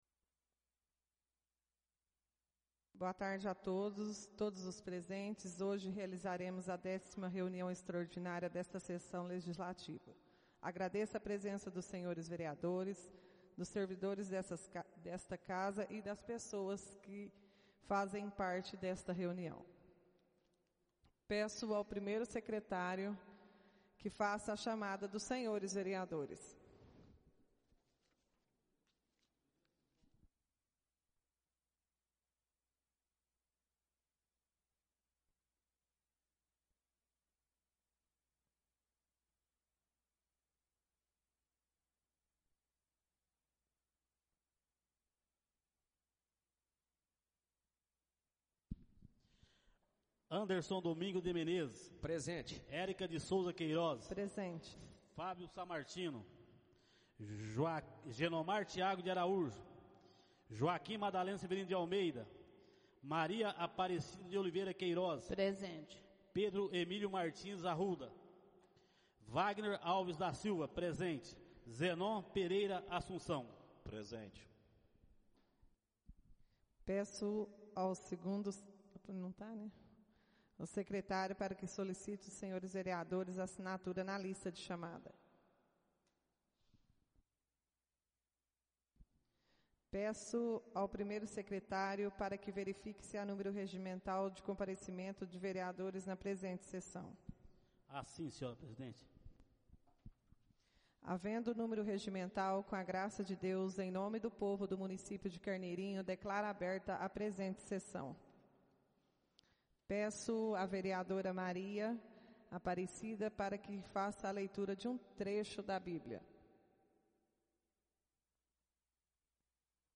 Áudio da 10.ª reunião extraordinária de 2022, realizada no dia 12 de Setembro de 2022, na sala de sessões da Câmara Municipal de Carneirinho, Estado de Minas Gerais.